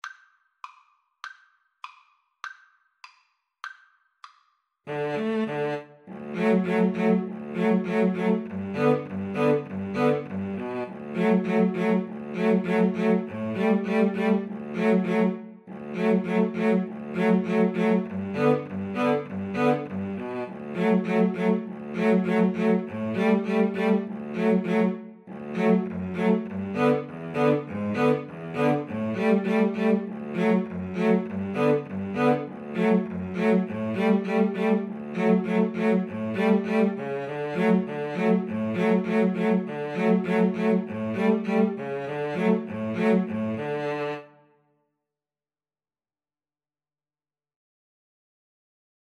D major (Sounding Pitch) (View more D major Music for Violin-Cello Duet )
2/4 (View more 2/4 Music)
Allegro (View more music marked Allegro)
Violin-Cello Duet  (View more Easy Violin-Cello Duet Music)
Traditional (View more Traditional Violin-Cello Duet Music)